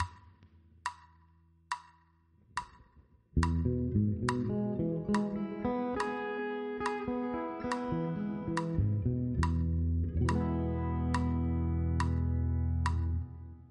Ex 1 – C-Dur Arpeggio – C-Shape